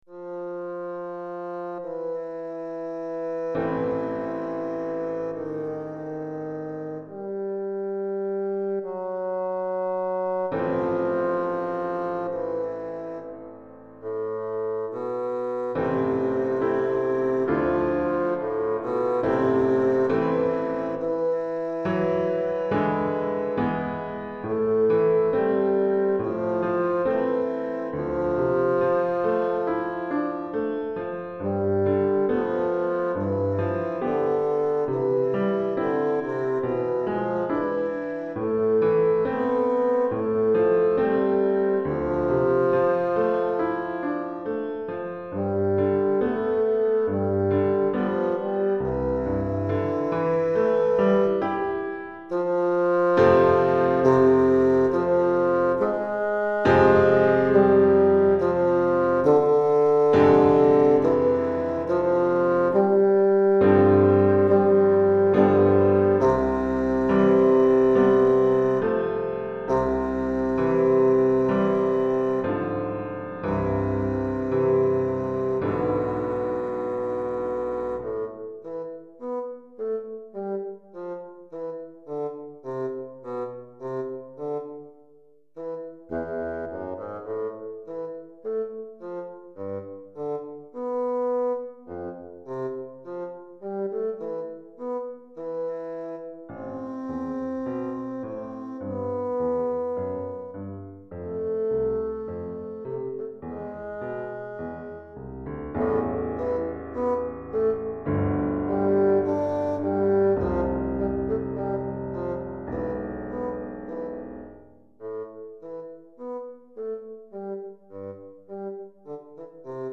Basson et Piano